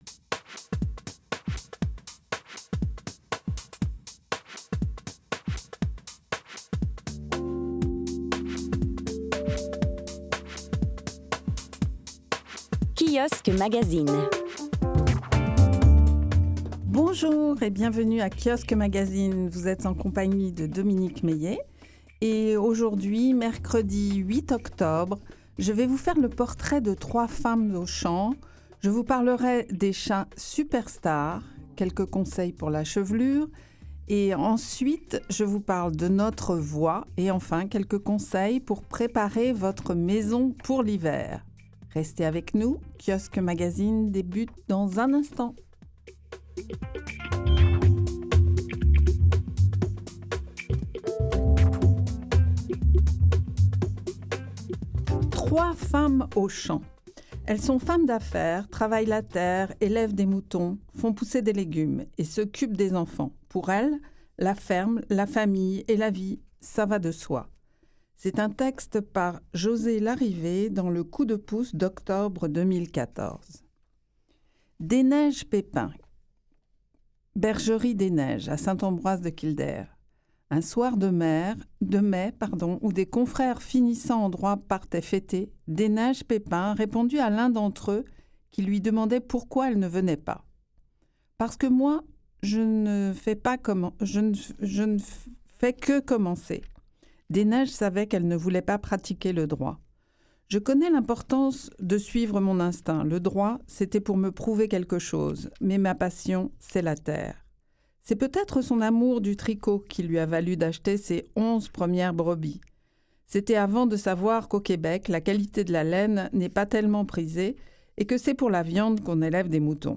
Mercredi : le meilleur des magazines Santé, cuisine et maison sous forme de revue de presse